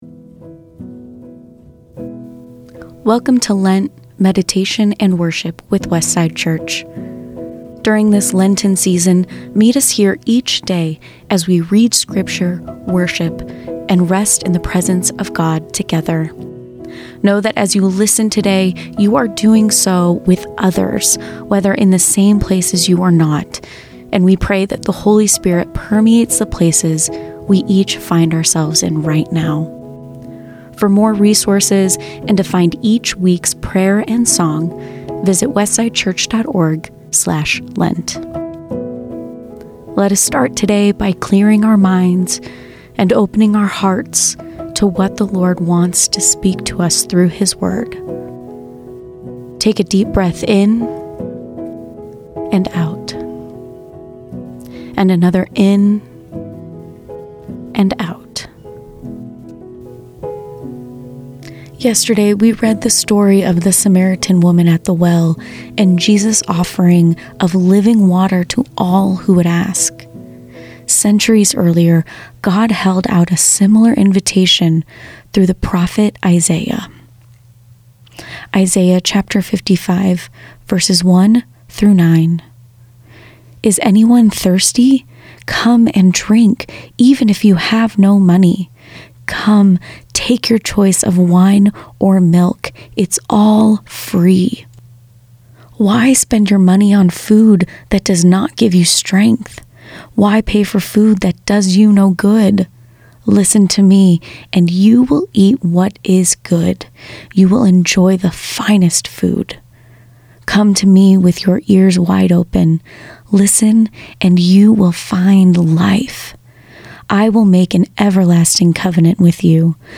A reading from Isaiah 55:1-9